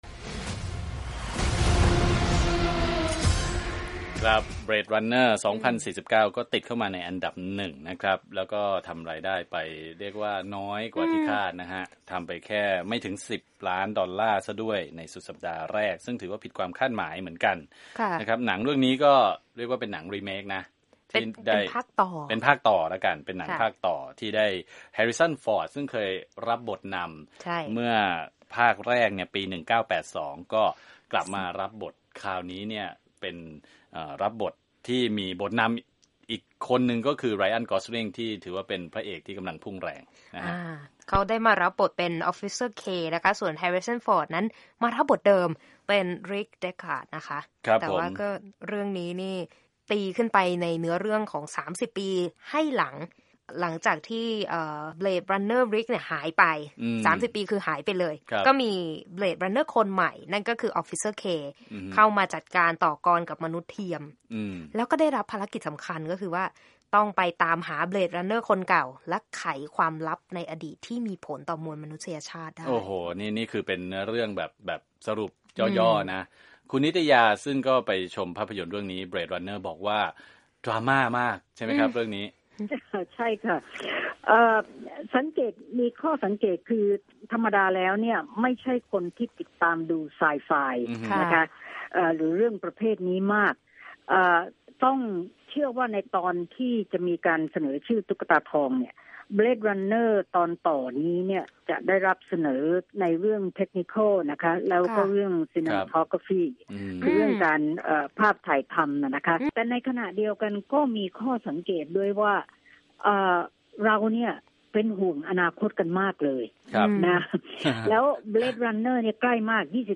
คุยภาพยนตร์ Blade Runner 2049